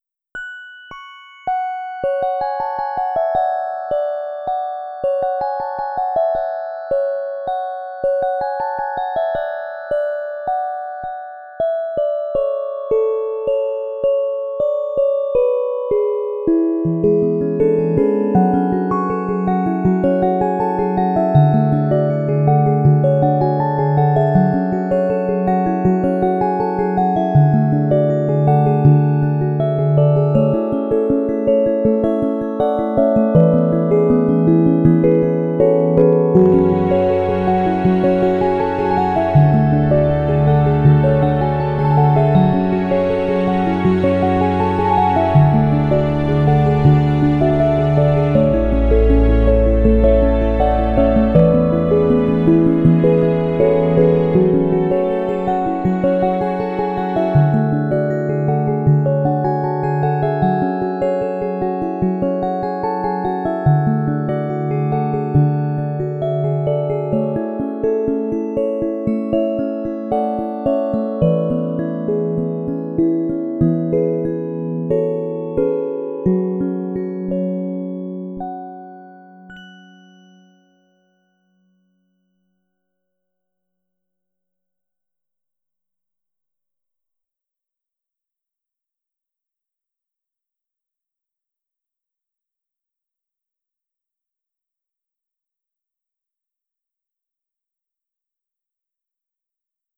Tags: Trio, Piano, Strings, Digital
However, short pretty melodies are what go on music boxes, so here we are. mp3 download wav download Files: wav mp3 Tags: Trio, Piano, Strings, Digital Plays: 542 Likes: 5
029 music box.wav